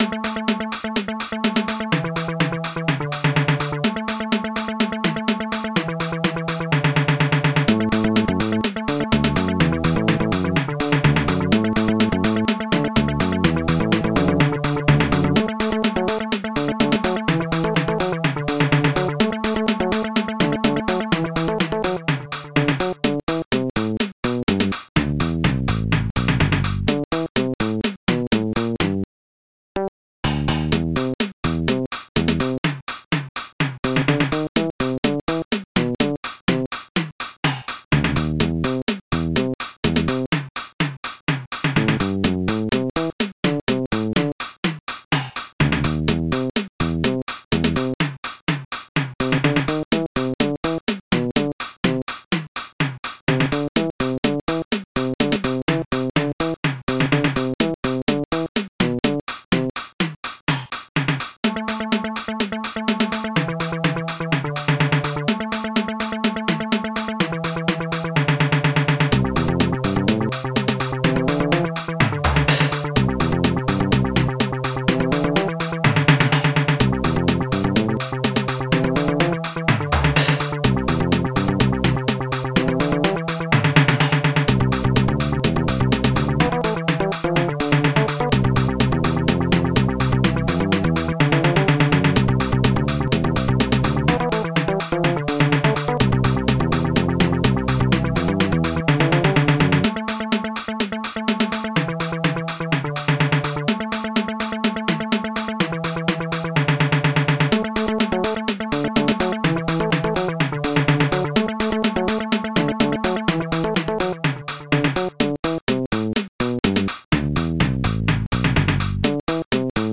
Protracker Module
Instruments rfbass filterbass tom3 hihat4